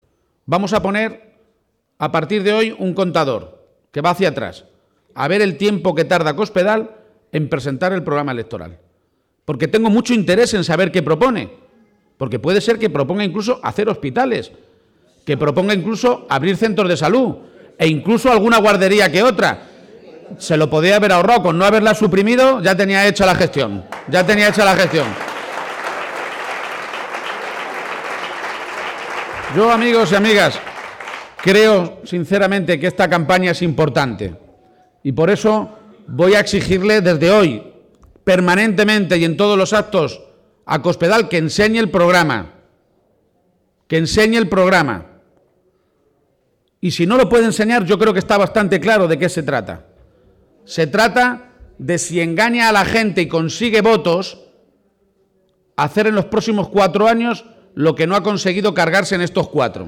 García-Page encaraba, pues, este último fin de semana de campaña con un acto público en Oropesa en el que sostenía que la prioridad en esta localidad, como en Talavera de la Reina, la ciudad más importante de esta comarca, la segunda en población y la primera por tasa de paro de Castilla-La Mancha, es el empleo, y decía que esta medida de dedicar el dos por ciento del presupuesto neto de la Junta a este problema «resume seguramente todas las medidas que hemos ido presentando para luchar contra el paro».